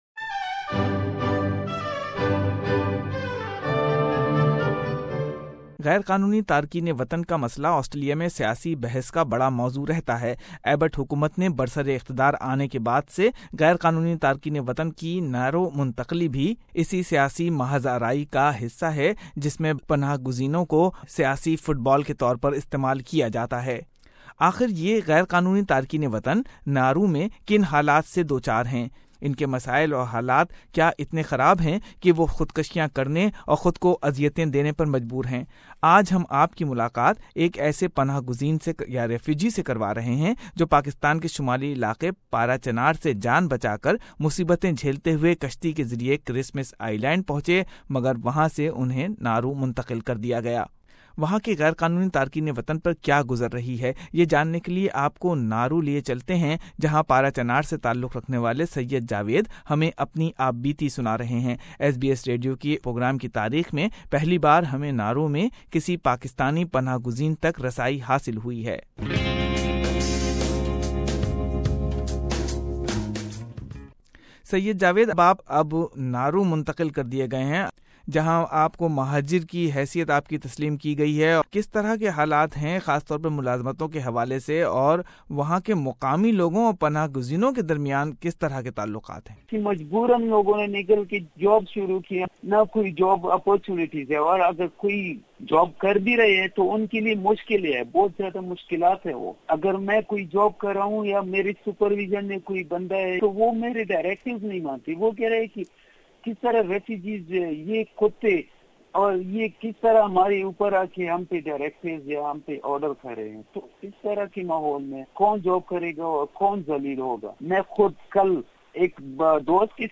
In an interview from the tiny Pacific island Nauru, A Pakistani refugees told SBS Radio Urdu program that they don't have enough facilities such as clean water, food, or work to sustain themselves and that they can't even afford phone calls to their families back home.